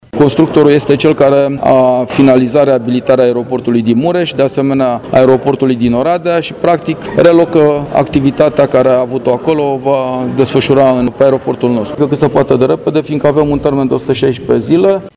Președintele Consiliului Județean Brașov, Adrian Veștea, a anunțat debutul acestei noi etape a lucrărilor: